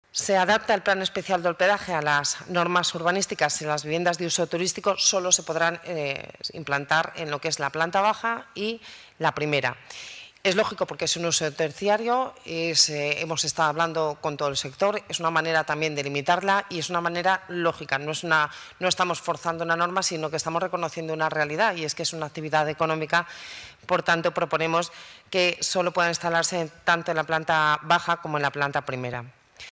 Escucha el Audio de la vicealcaldesa de Madrid, Begoña Villacís
Almeida, Villacís y Sanz durante la rueda de prensa posterior a la Junta de Gobierno